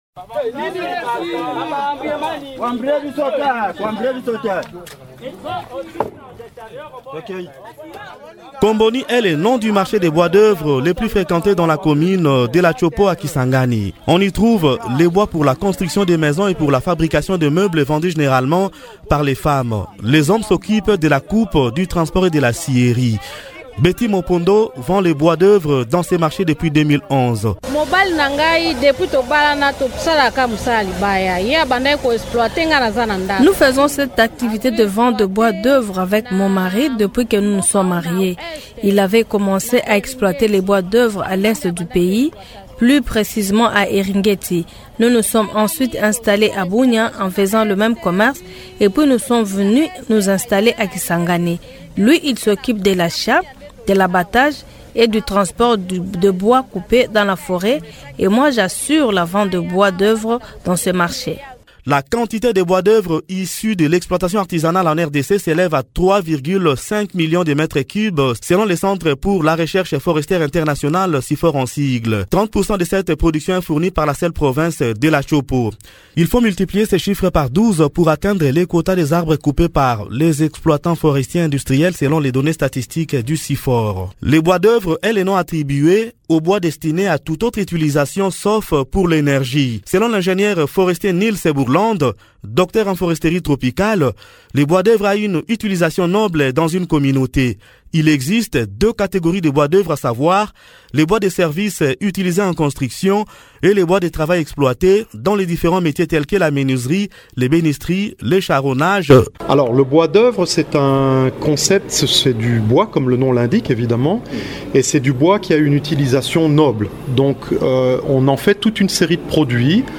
[reportage radio]
Reportage-Filiere-des-bois-d-oeuvre-dans-la-province-de-la-TSHOPO-RDC-08-minutes-45-secondes.mp3